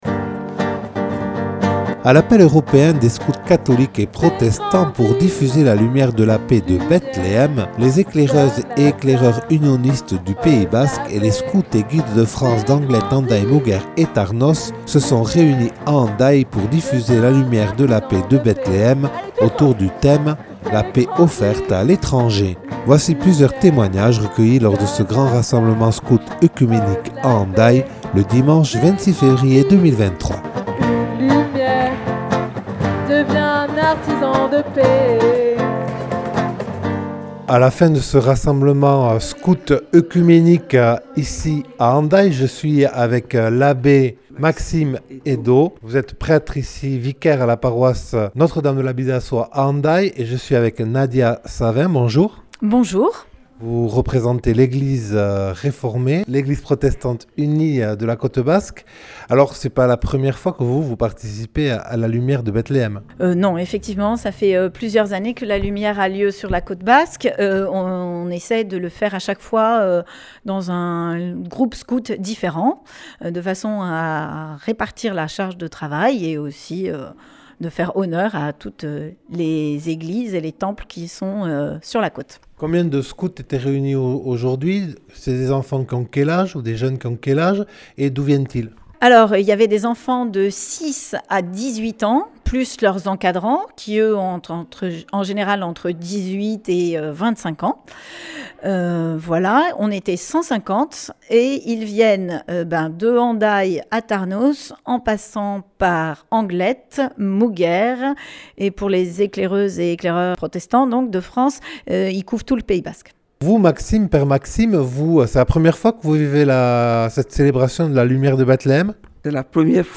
A l’appel européen des Scouts catholiques et protestants, un beau rassemblement oecuménique s’est déroulé le 26 février 2023 à Hendaye avec 150 enfants et jeunes : reportage.